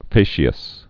(fāshē-əs, -ăs)